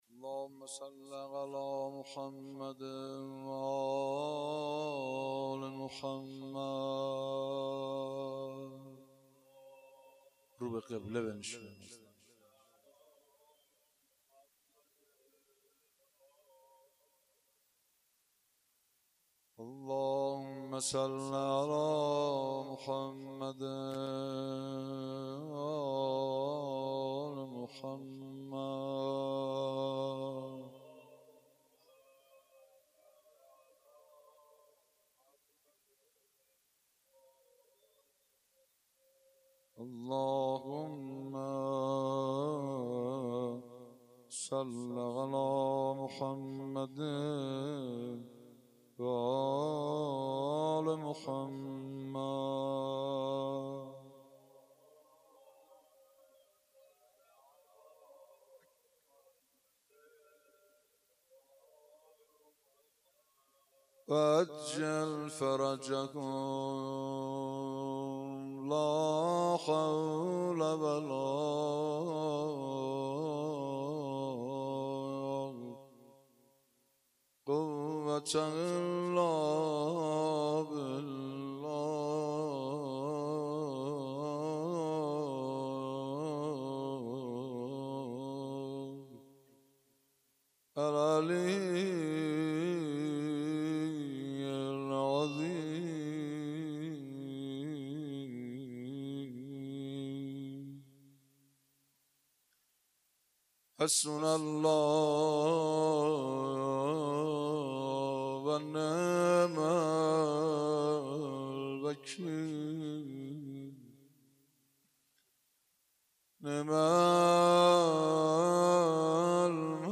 روضه و مناجات
اگر برآید چو مرغی ز پیکر خسته ام پر روضه محمود کریمی